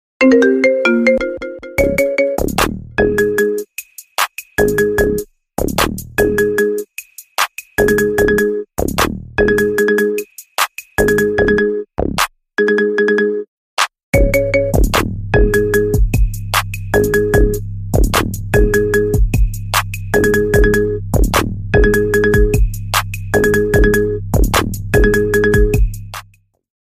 # Рингтоны Без Слов
# Рингтоны Ремиксы